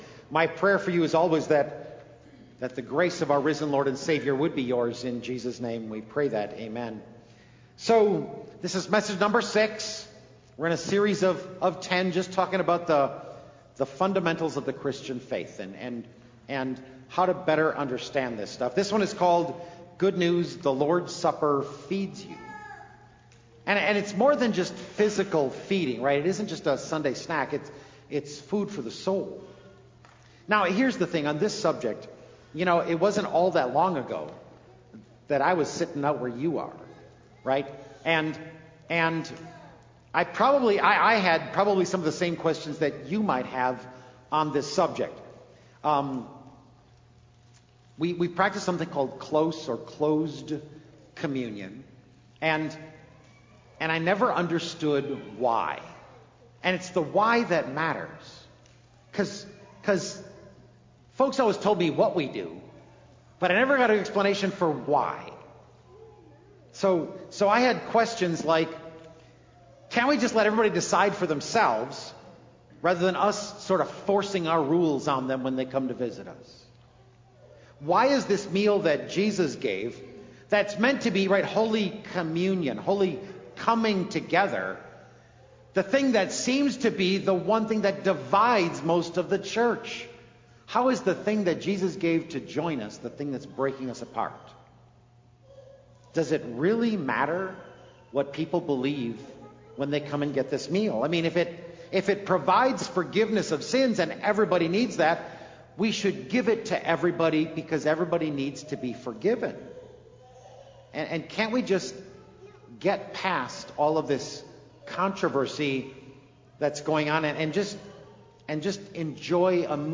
07-29-Good-News-The-Lords-Supper-Feeds-You-Sermon-Audio-CD.mp3